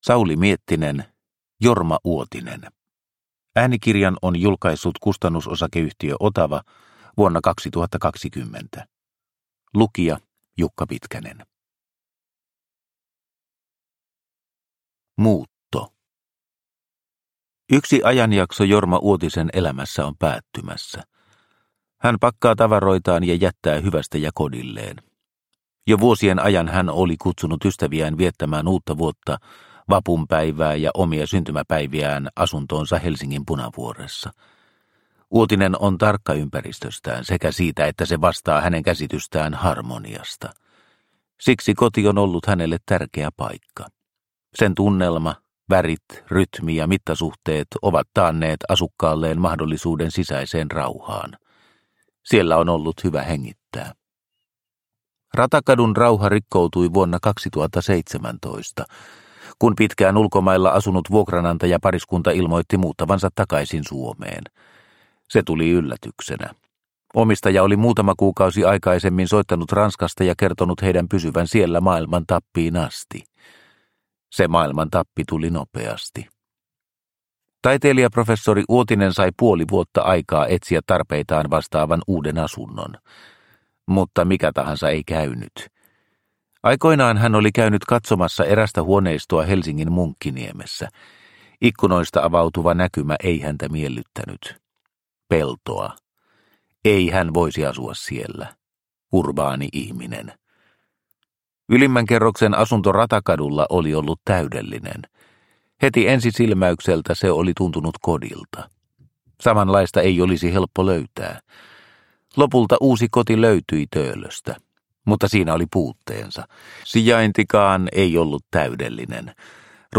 Jorma Uotinen – Ljudbok – Laddas ner